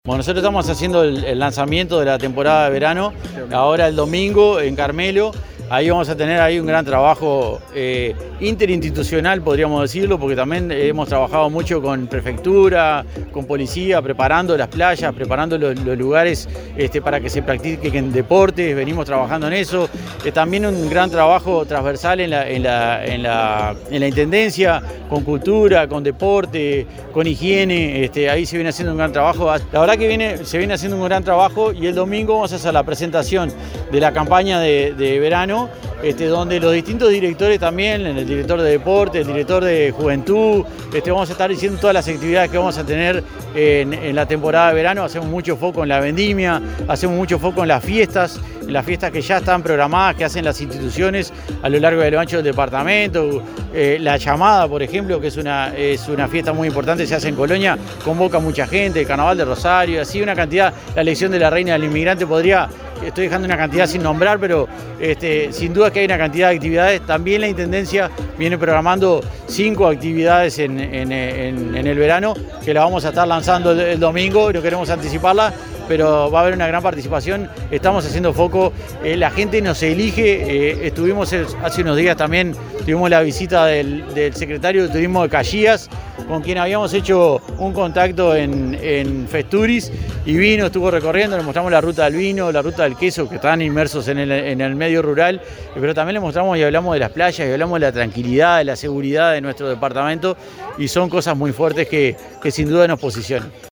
Escuchamos al director de Turismo, Martín Alvarez…